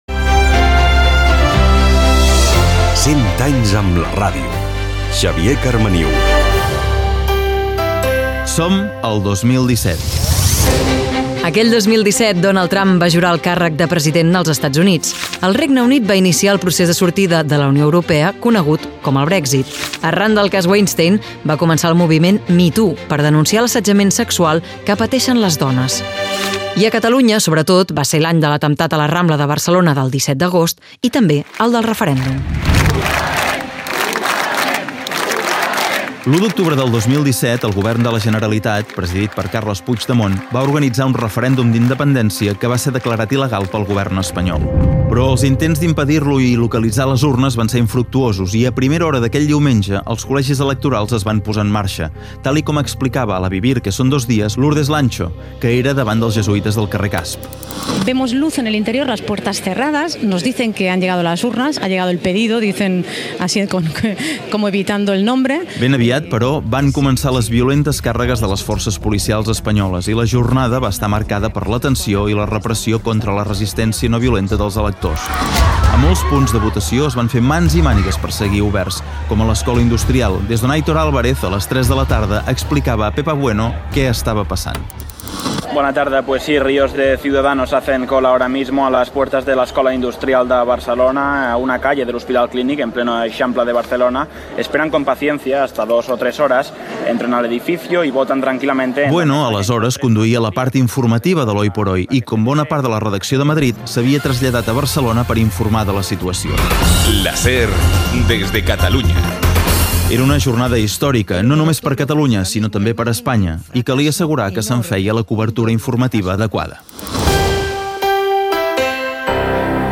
Divulgació